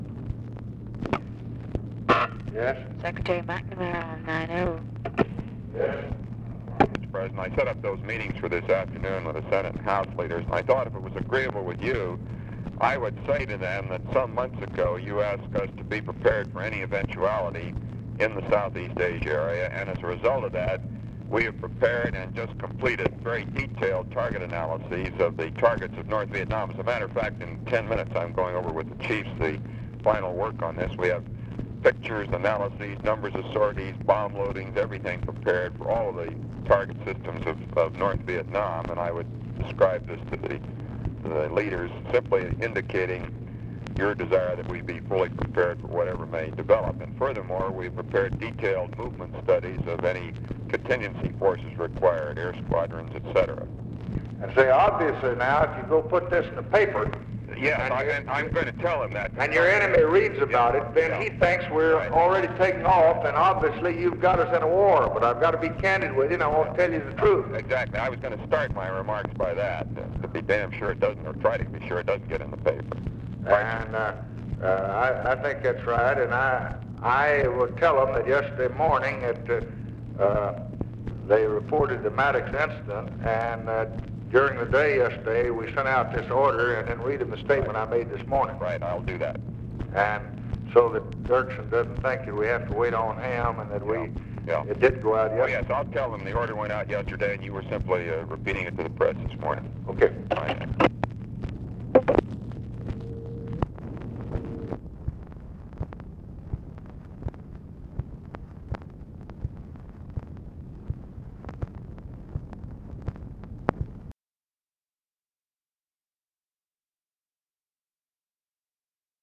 Conversation with ROBERT MCNAMARA, August 3, 1964
Secret White House Tapes